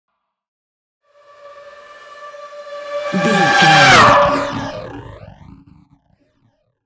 Sci fi vehicle pass by
Sound Effects
futuristic
pass by
vehicle